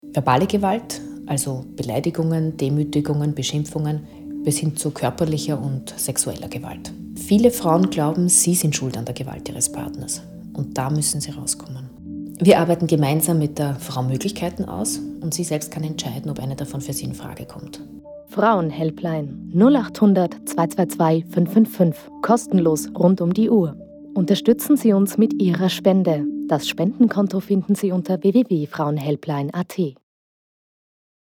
Hörspot
Frauenhaus Radiospot_Radiospot.mp3